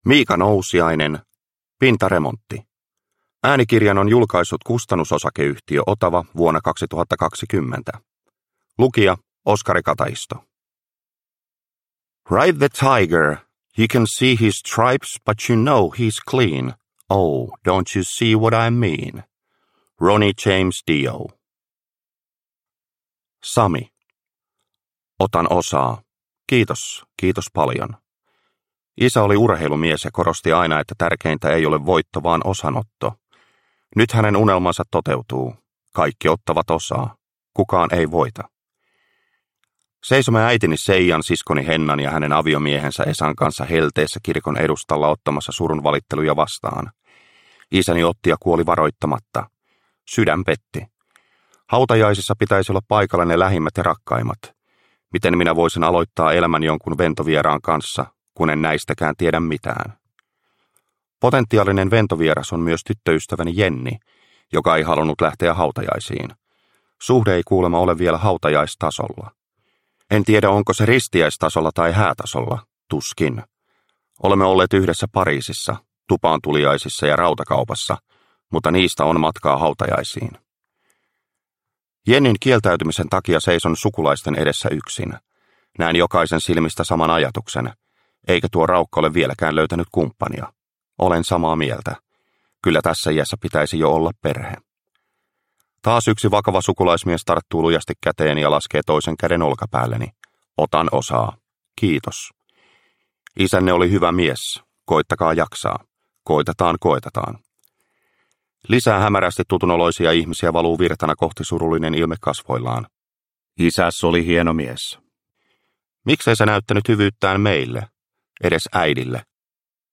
Pintaremontti – Ljudbok